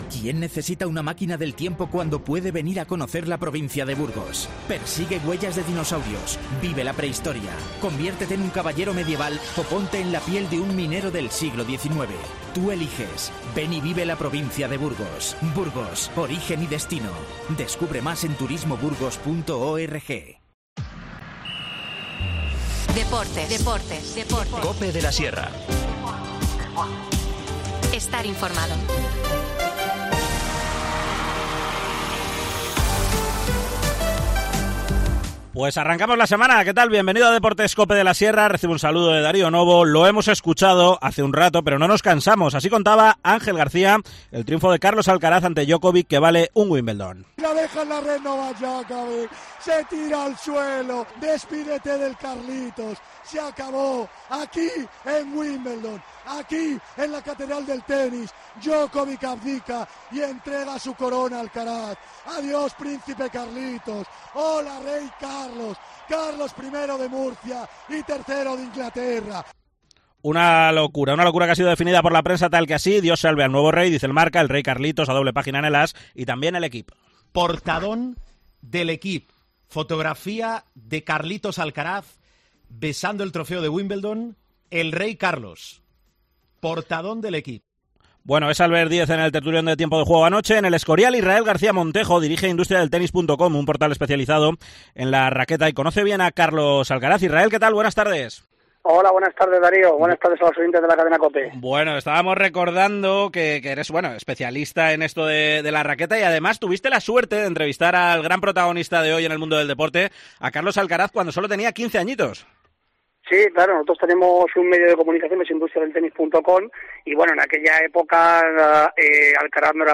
periodista especializado en tenis que ha seguido la trayectoria del murciano desde los 15 años.